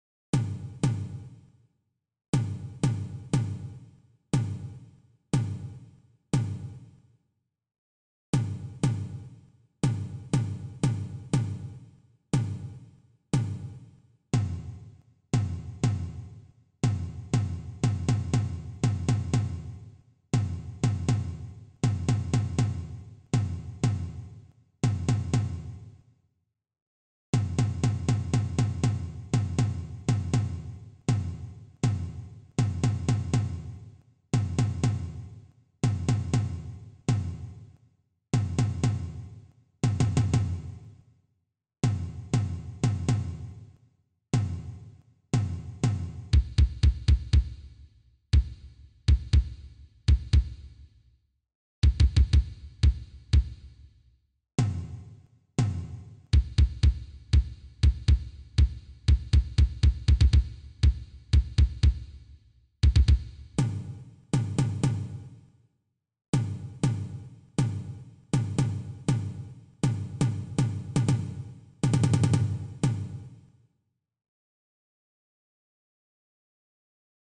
Uebung-4-Rhythmusuebung.mp3